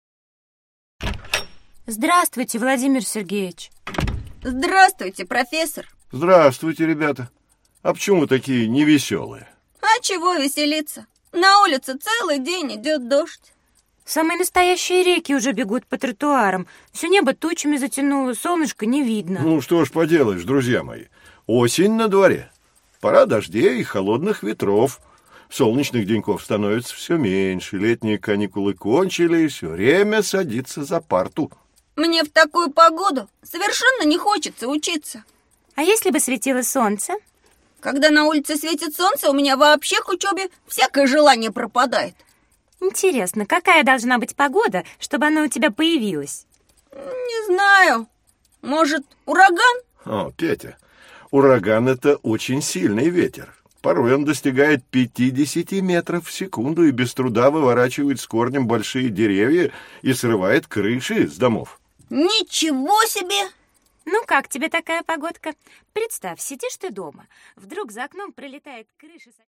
Аудиокнига Наша планета: Планета Земля | Библиотека аудиокниг